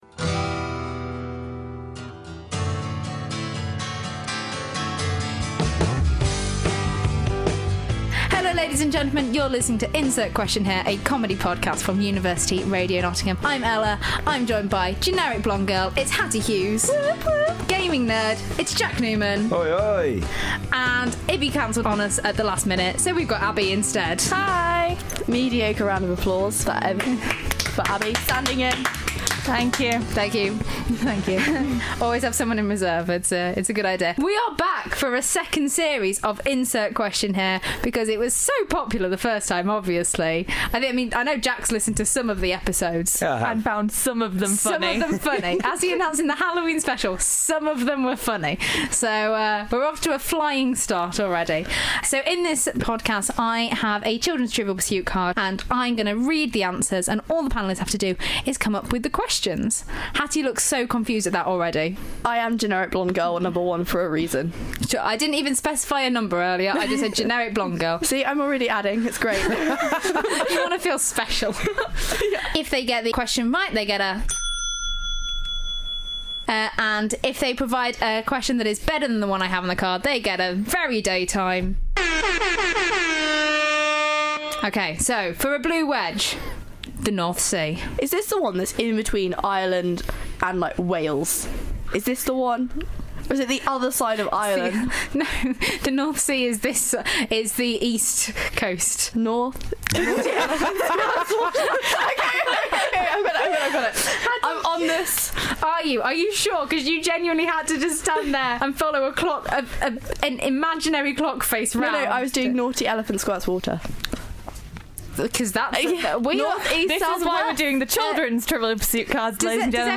4 students. 1 pack of children's Trivial Pursuit cards.
Your favourite trivial-pursuit-but-backwards-based-comedy-quiz-show-kind-of-thing podcast is back for a second series (and no one is more surprised than we are).